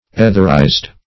Search Result for " etherized" : The Collaborative International Dictionary of English v.0.48: Etherize \E"ther*ize\, v. t. [imp.